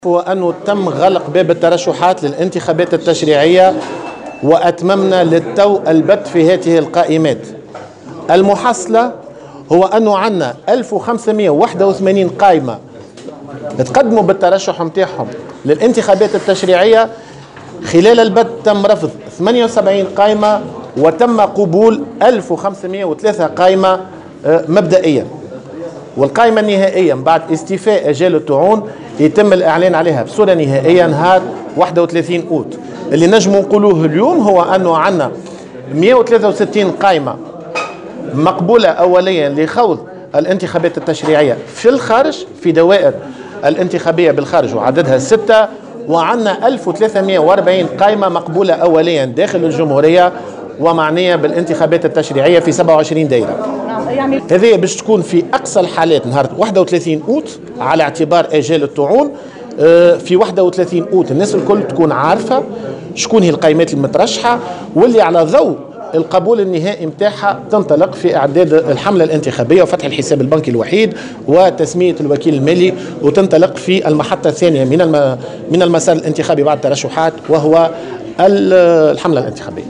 وأكد في تصريح لمراسلة "الجوهرة أف أم" على هامش الندوة أنه تم قبول 1503 قائمات مبدئيا من بين 1581 قائمة تقدّمت للترشّح للانتخابات التّشريعية، مشيرا إلى انه تم بالتالي رفض 78 قائمة. وأضاف أن القائمات المقبولة بصفة أولية تتوزع بين 1340 قائمة داخل الجمهورية ومن خلال 27 دائرة و163 قائمة مقبولة أولية في الدوائر الانتخابية بالخارج وعددها 6.